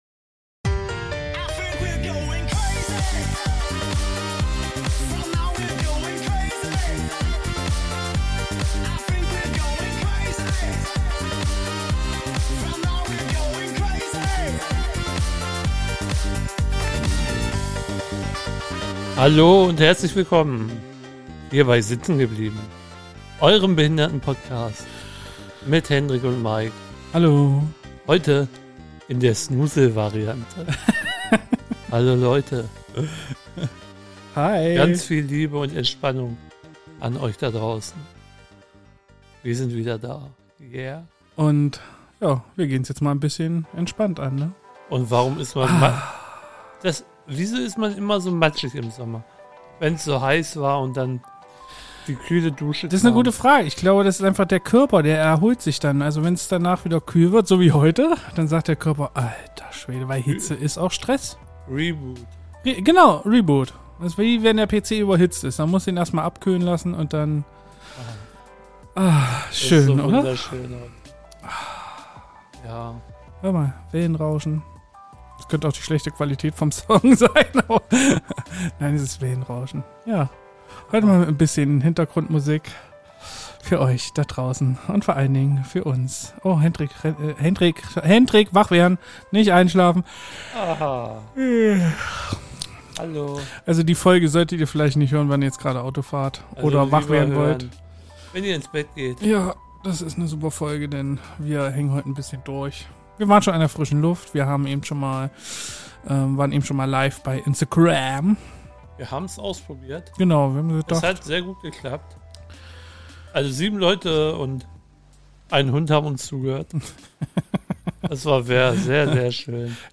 Die erste große Hitze ist durch und wir genießen die Abkühlung mit kalten Drinks und entspannter Musik.